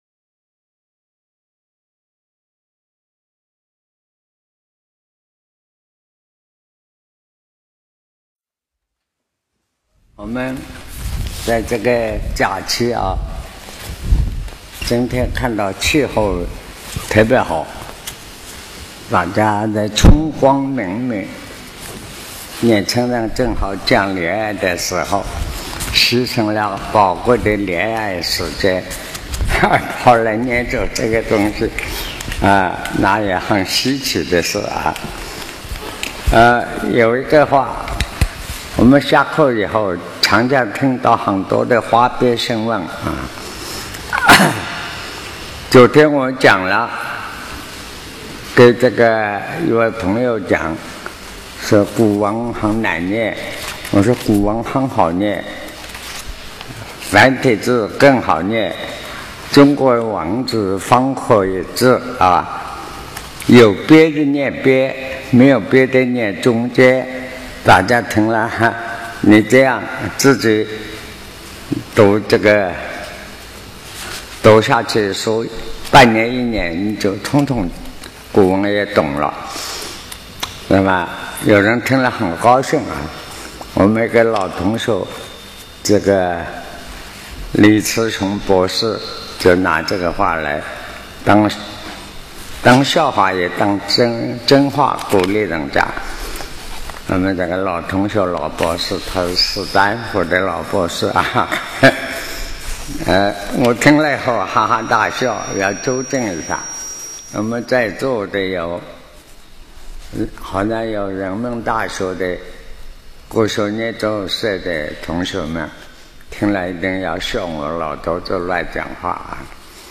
南怀瑾先生所讲《生命科学与黄帝内经》辅导学习课程